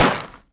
Hit5.aif